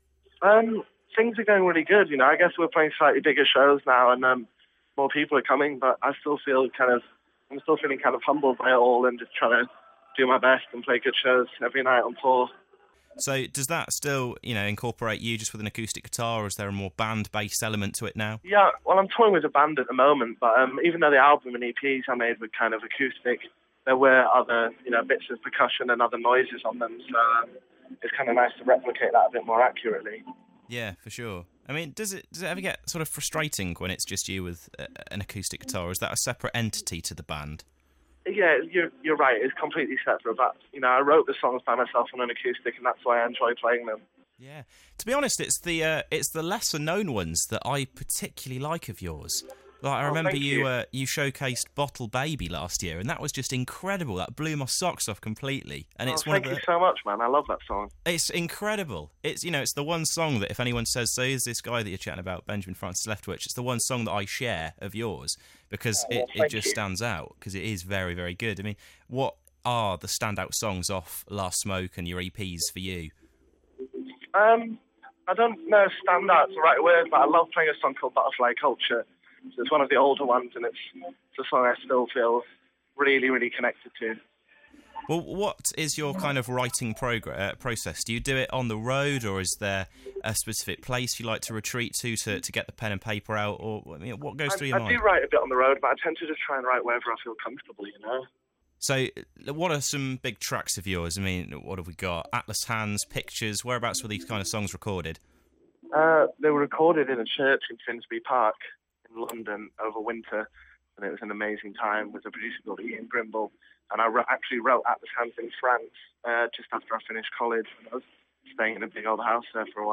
Benjamin Francis Leftwich took some time out of his busy schedule to pick up the phone to The Source